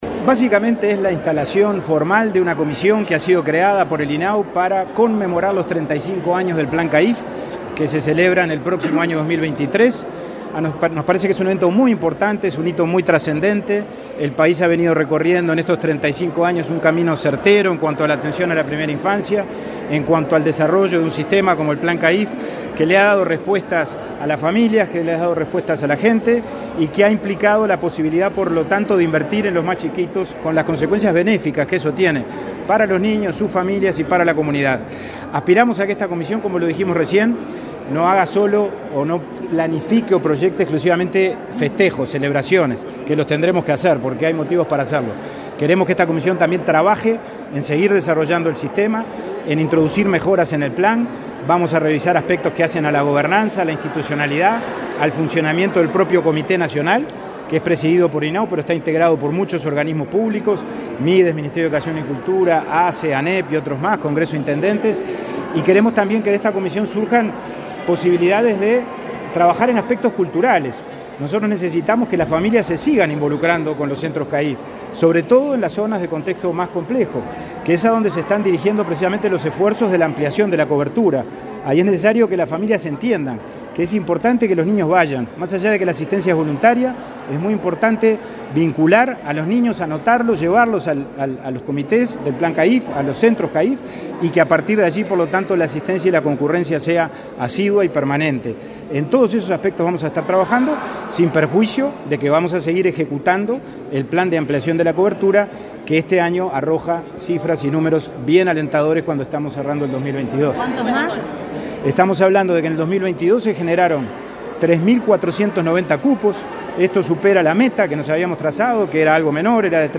Declaraciones del presidente del INAU, Pablo Abdala
El presidente del INAU, Pablo Abdala, dialogó con la prensa luego de que se instalara la comisión creada en el marco de los 35 años del Plan CAIF.